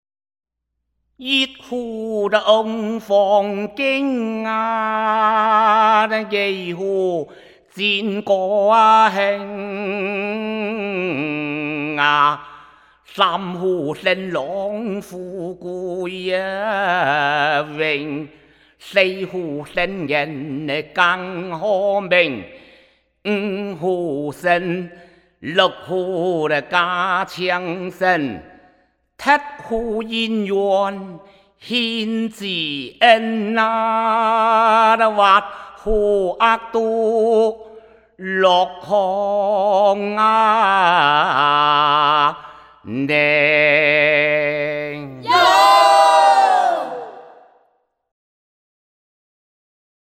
开平民歌集